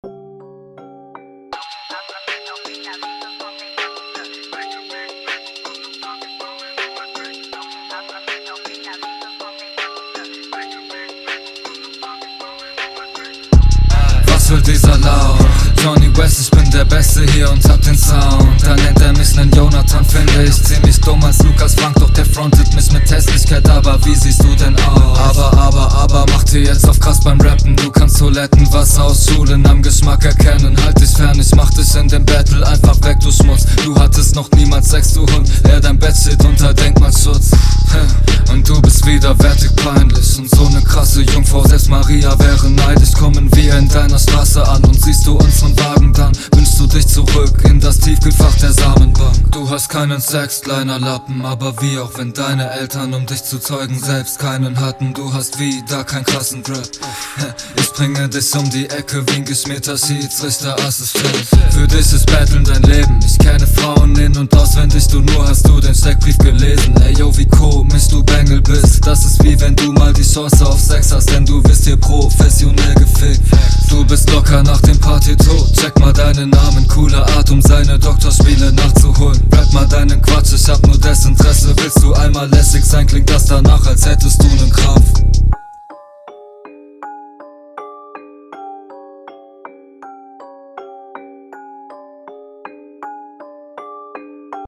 sehr nicer Vibe wieder.